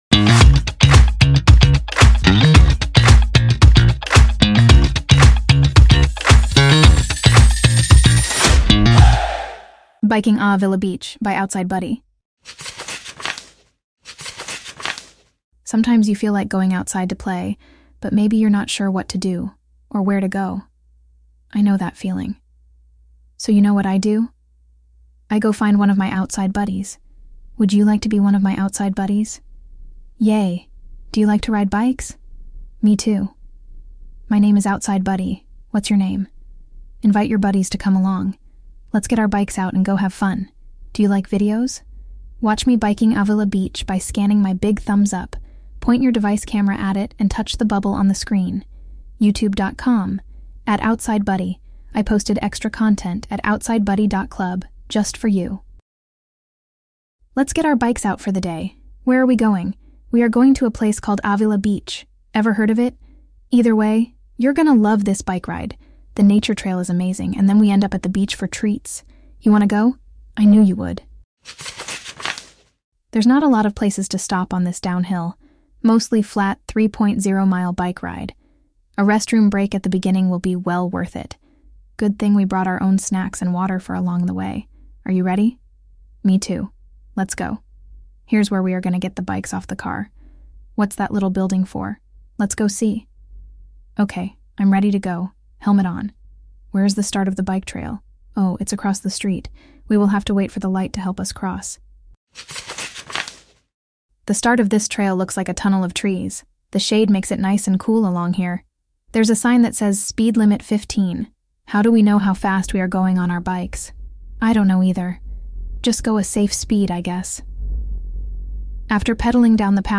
Free AudioBook!